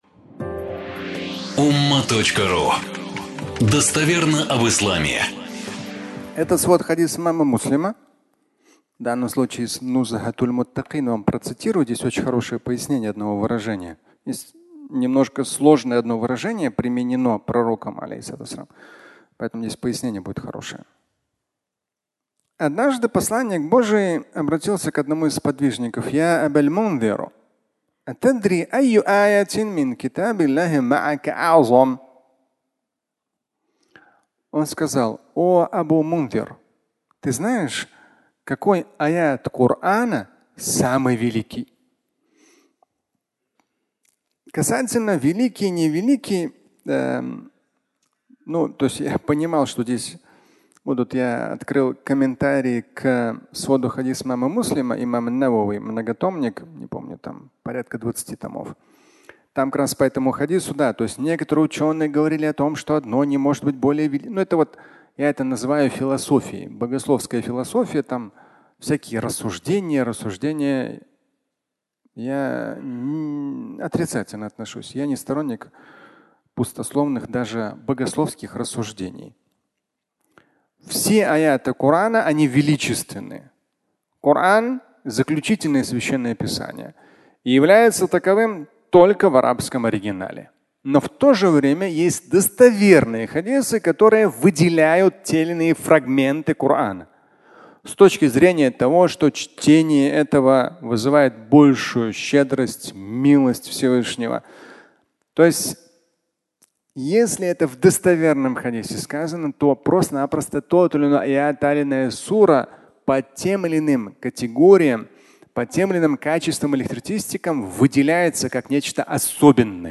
Лучший аят Корана (аудиолекция)